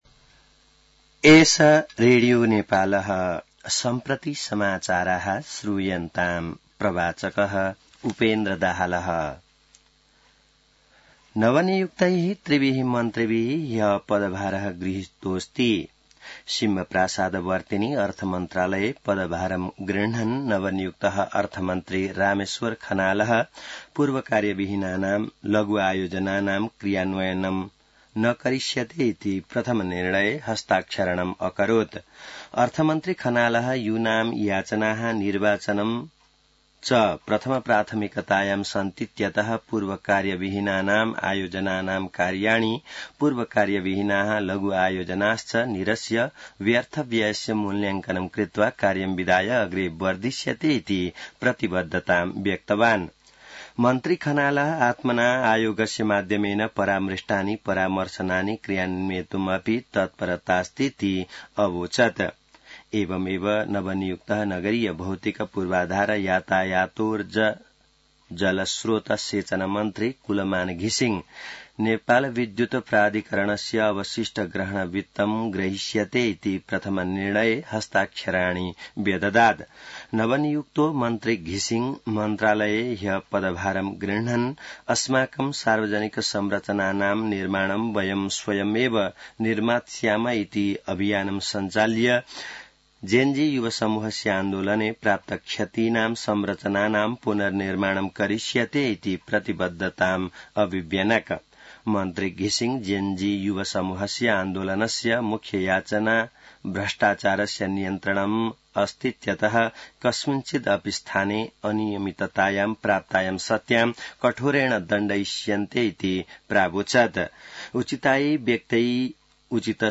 An online outlet of Nepal's national radio broadcaster
संस्कृत समाचार : ३१ भदौ , २०८२